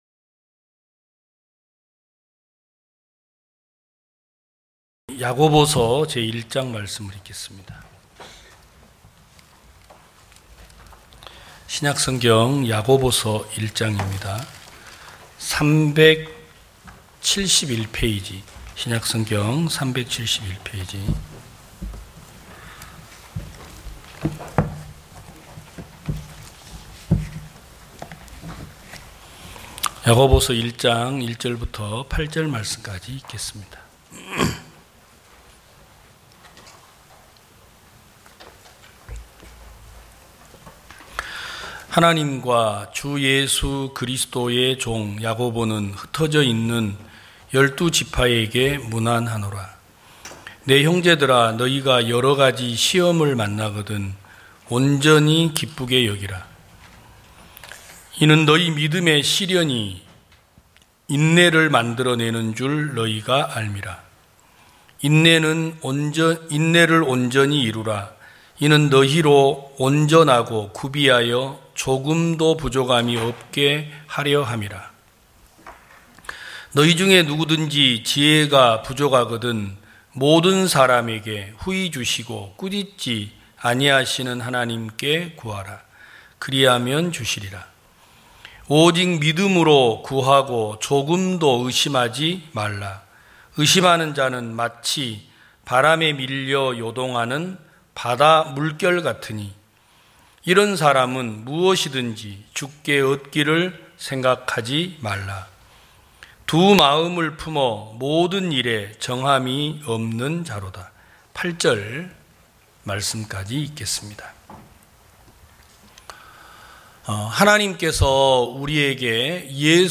2022년 07월 17일 기쁜소식부산대연교회 주일오전예배
성도들이 모두 교회에 모여 말씀을 듣는 주일 예배의 설교는, 한 주간 우리 마음을 채웠던 생각을 내려두고 하나님의 말씀으로 가득 채우는 시간입니다.